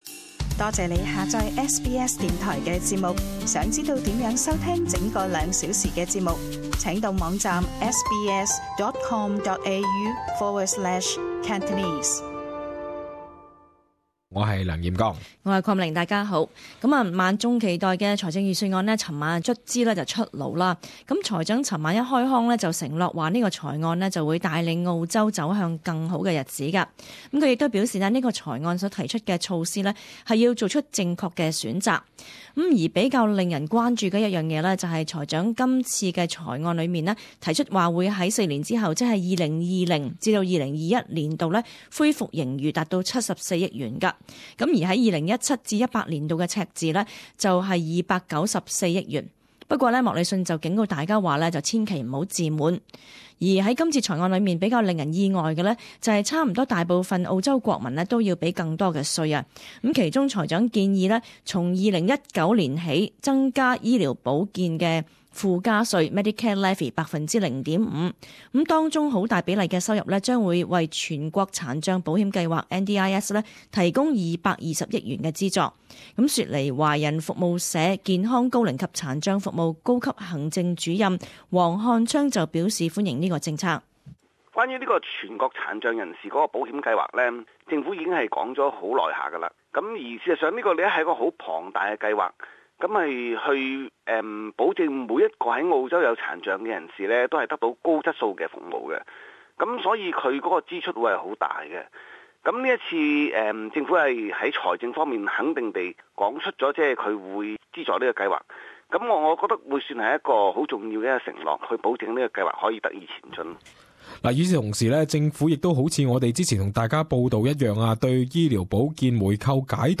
【時事報導】2017財案對你有甚麼影響？